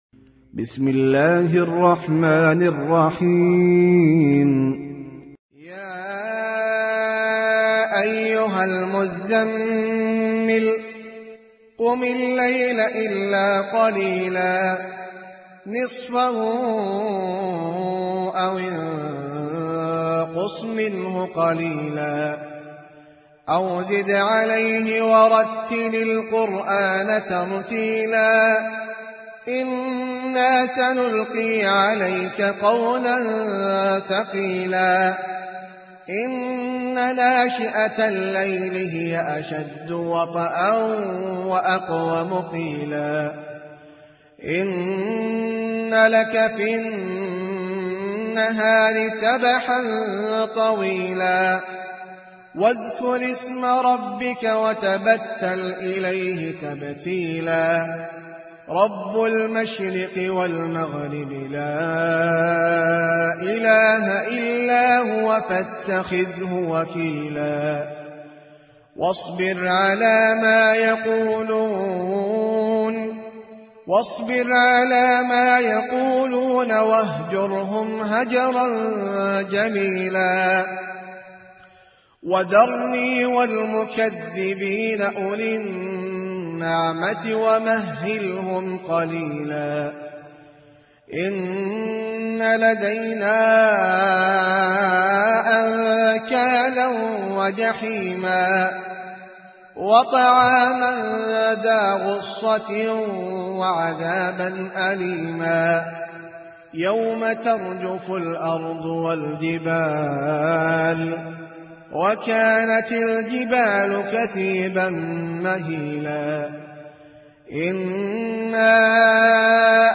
سورة المزمل - المصحف المرتل (برواية حفص عن عاصم)
جودة عالية